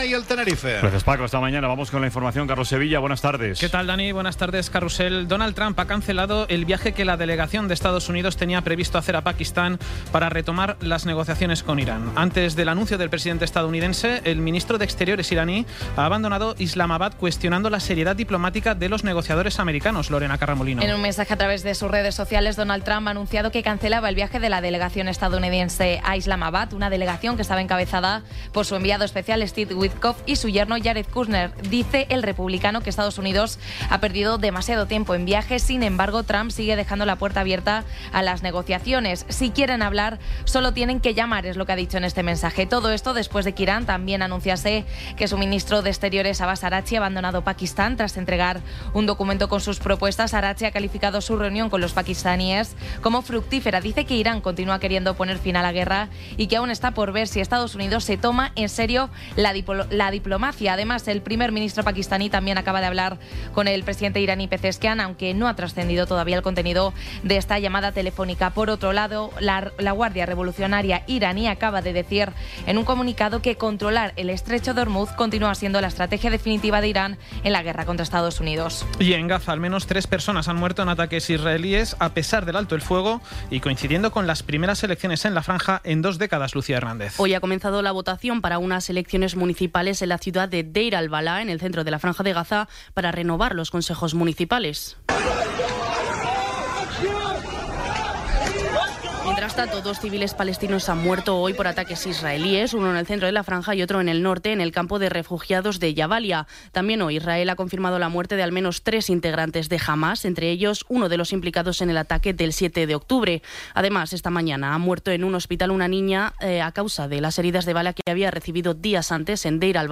Resumen informativo con las noticias más destacadas del 25 de abril de 2026 a las ocho de la tarde.